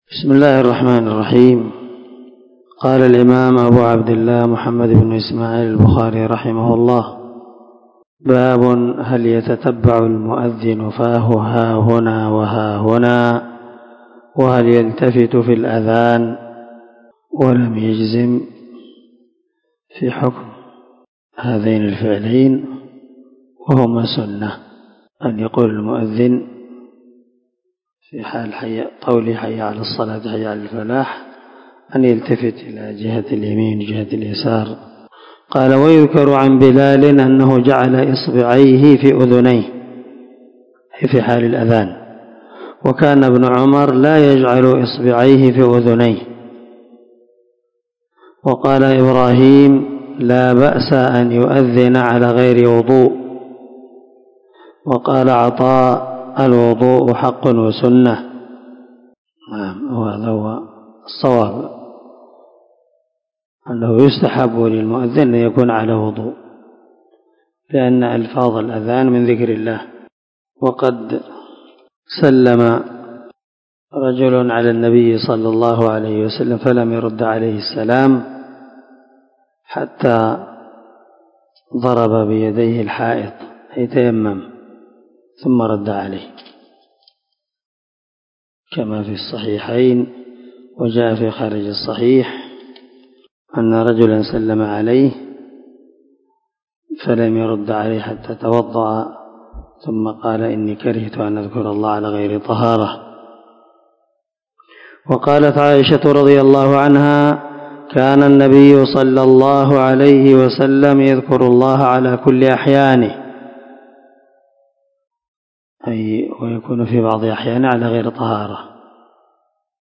دار الحديث- المَحاوِلة- الصبيحة